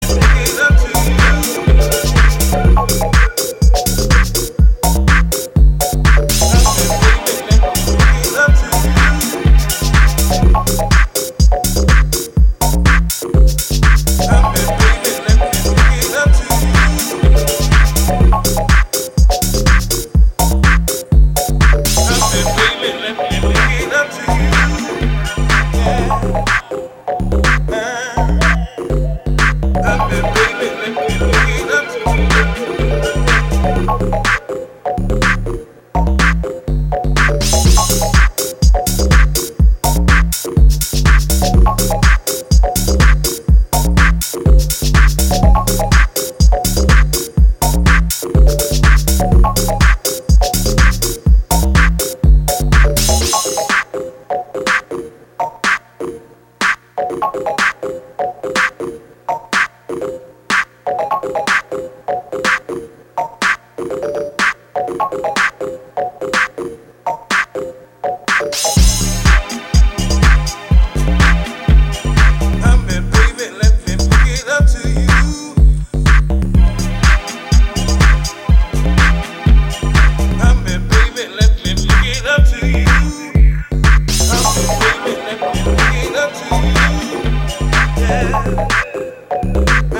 このA面を筆頭に、オーセンティックでありながらもヒプノティックな志向に向かう、90年代中盤地下の空気感を秘めた全3曲。